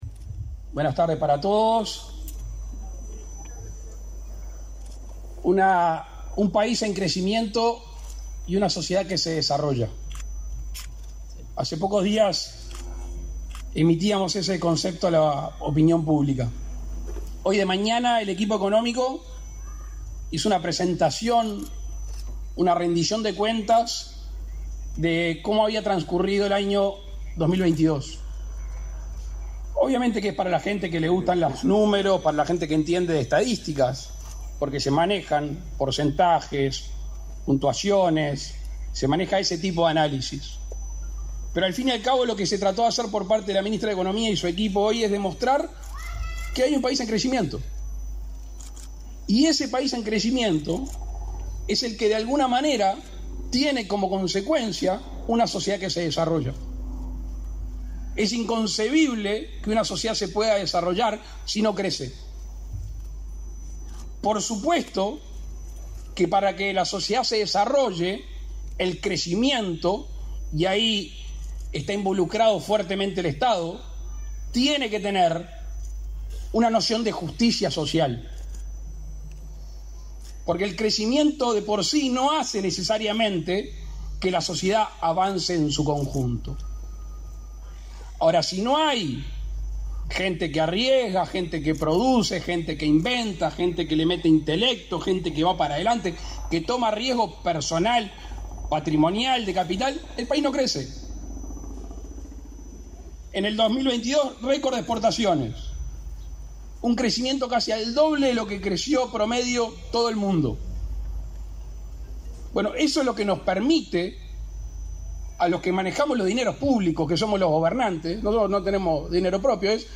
Palabras del presidente de la República, Luis Lacalle Pou
El presidente de la República, Luis Lacalle Pou, participó, este 15 de febrero, en la inauguración de obras de remodelación y acondicionamiento del